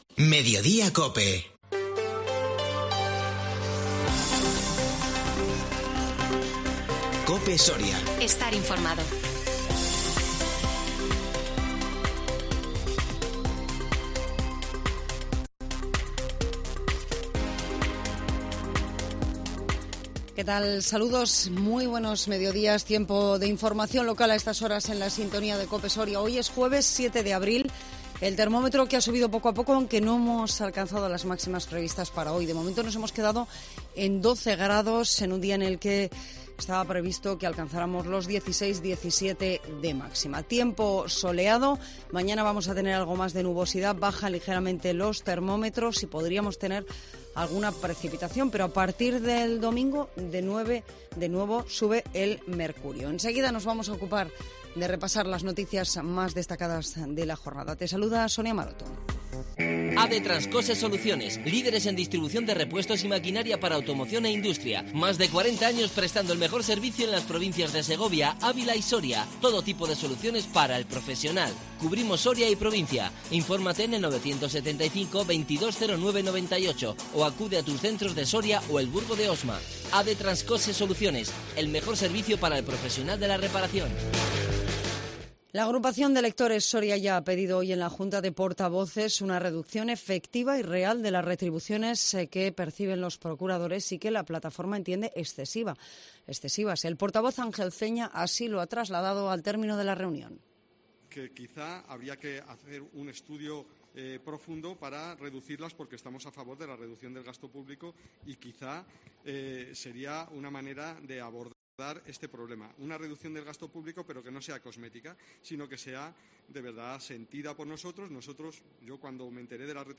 INFORMATIVO MEDIODÍA COPE SORIA 7 ABRIL 2022